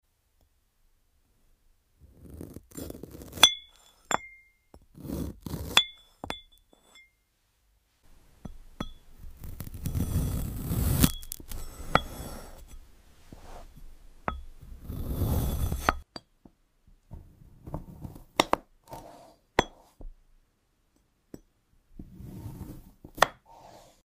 ASMR glass cutting sounds | sound effects free download
Experience the crisp and calming sound of glass being cut with precision. Perfect for ASMR lovers and those who enjoy satisfying, relaxing audio.